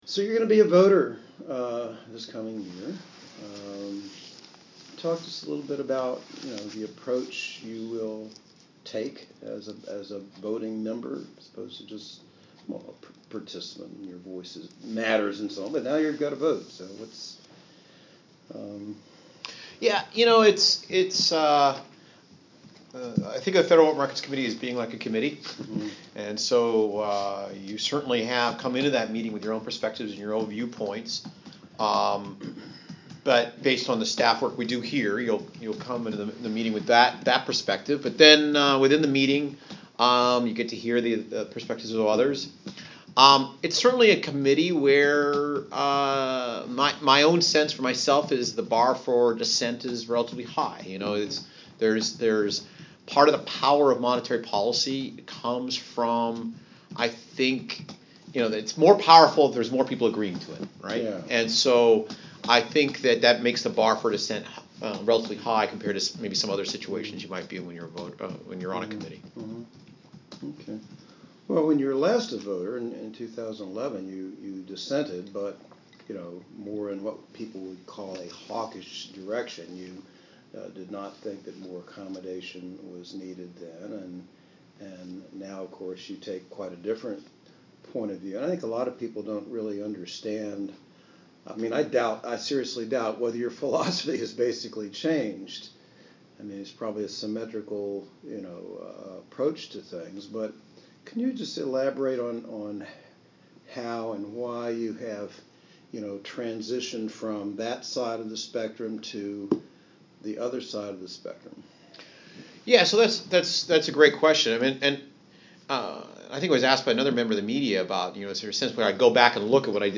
Interview with Market News International on Nov. 19th (Audio) | Federal Reserve Bank of Minneapolis